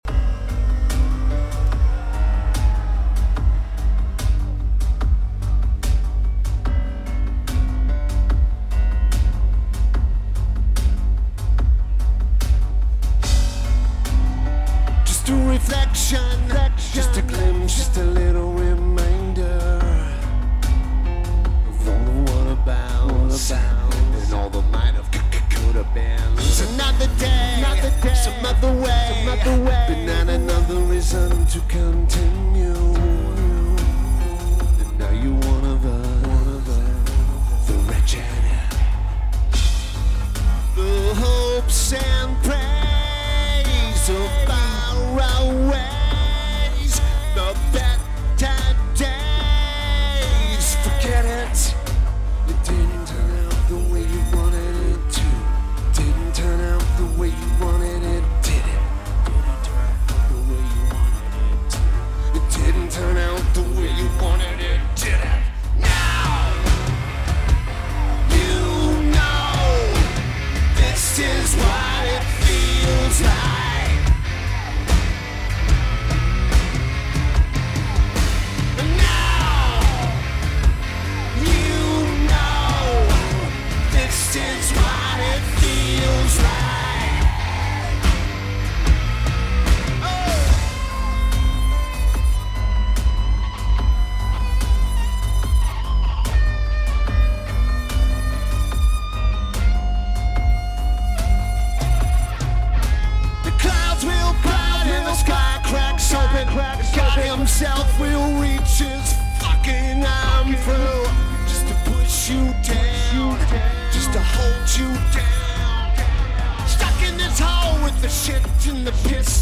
Red Hat Amphitheater
I taped in front of the left stack.